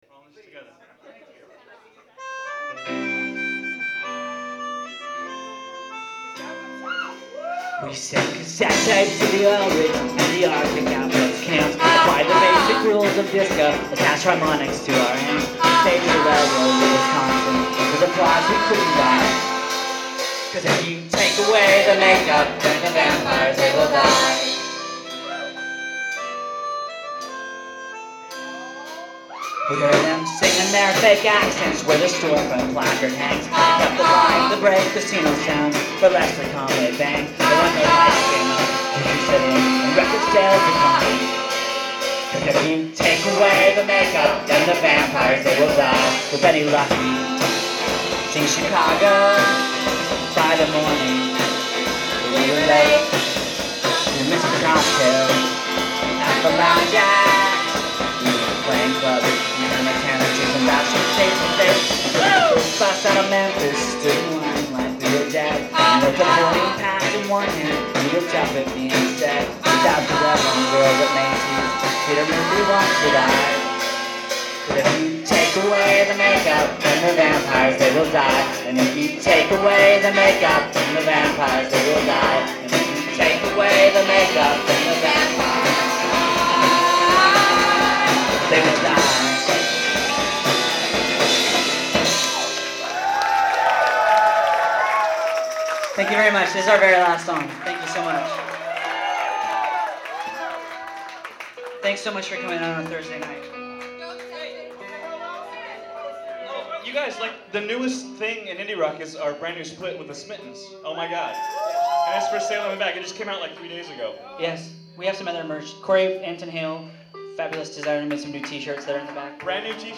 Live at PA’s Lounge
in Somerville, MA